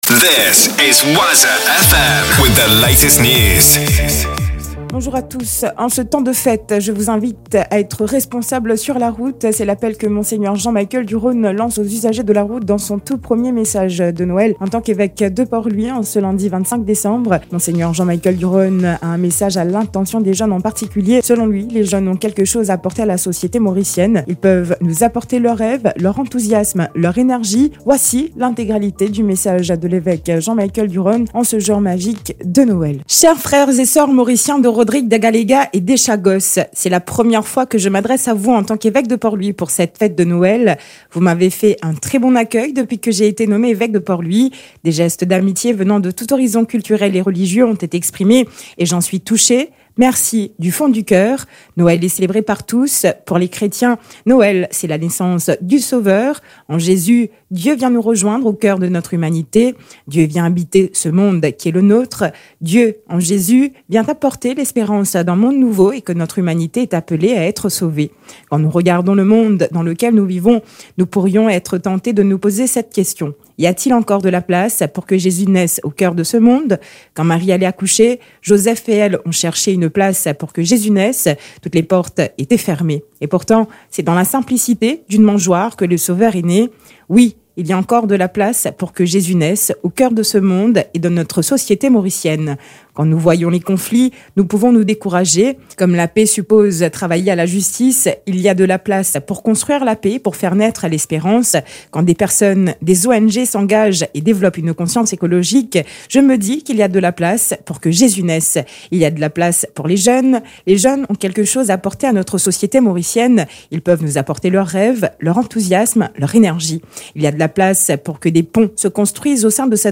NEWS 8h - 25.12.23